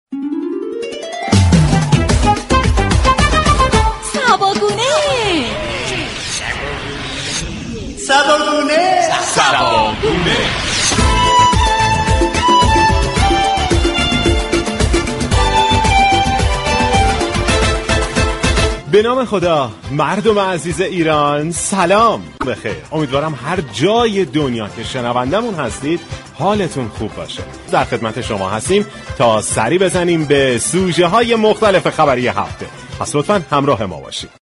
برنامه طنز سیاسی "صبا گونه " به بررسی ماجرای بحران بنزین در انگلیس می پردازد